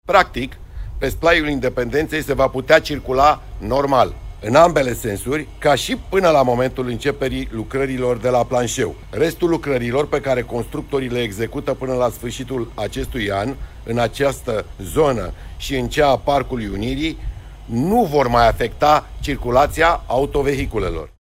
Daniel Băluță, primarul Sectorului 4: „Pe Splaiul Independenței se va putea circula normal”